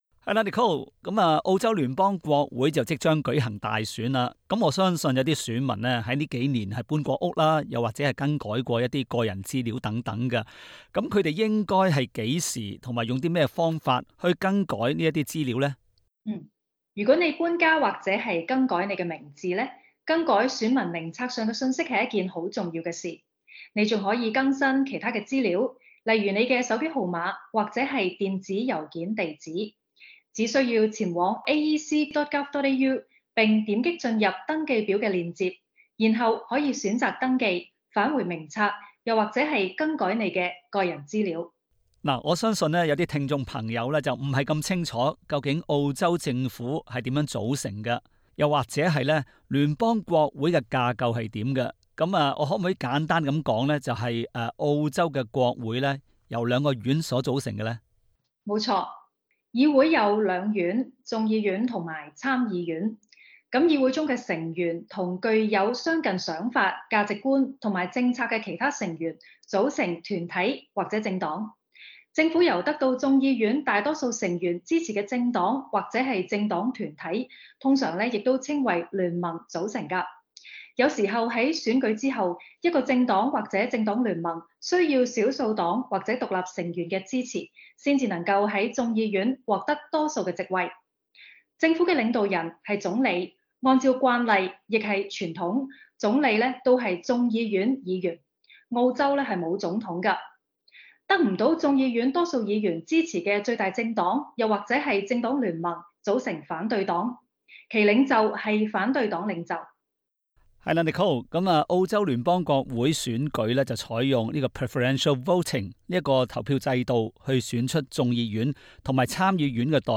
Source: AAP SBS廣東話節目 View Podcast Series Follow and Subscribe Apple Podcasts YouTube Spotify Download (10.83MB) Download the SBS Audio app Available on iOS and Android 下屆聯邦大選即將於下個月 (五月) 舉行，不知道大家是否合資格的選民呢？